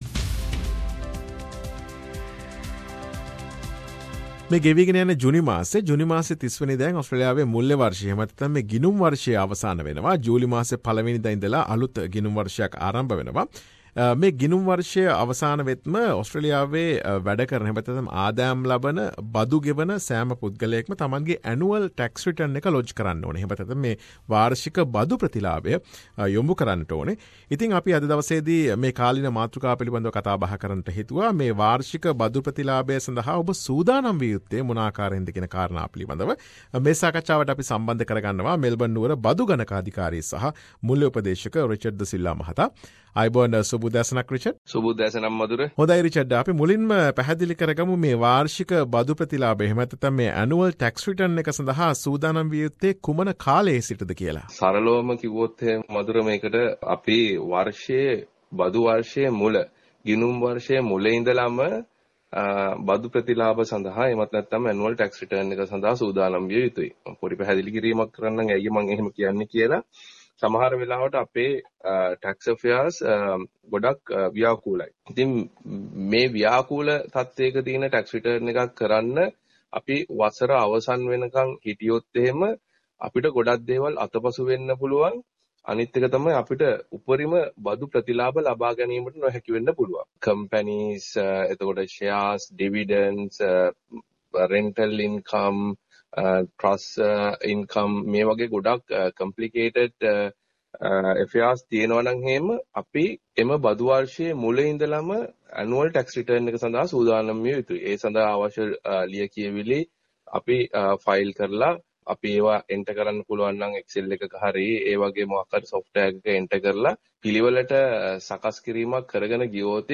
SBS සිංහල වැඩසටහන සිදු කළ සාකච්ඡාව.